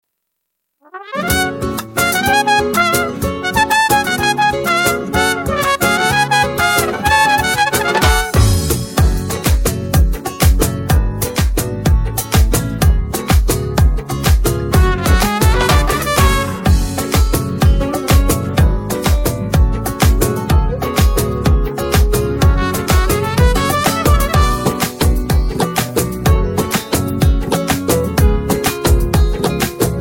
موزیک بی کلام